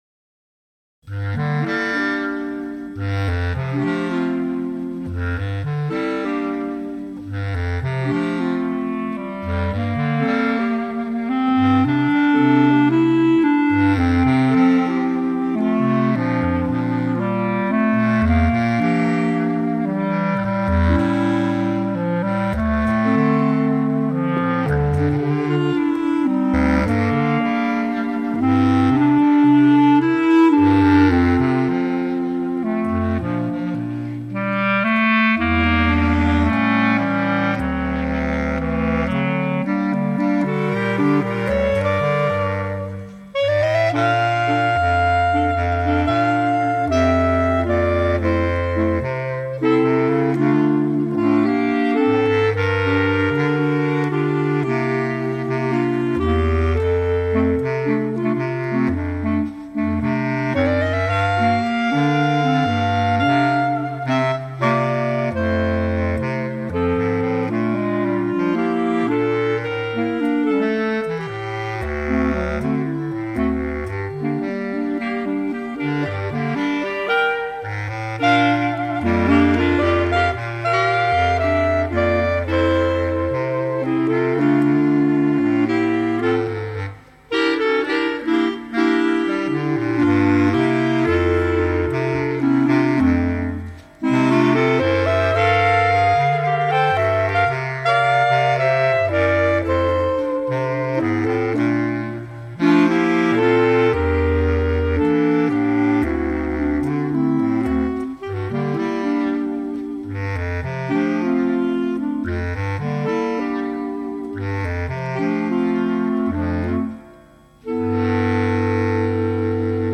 Bb Clarinet Range: E1 to A3. Bass Clarinet Lowest Note: E1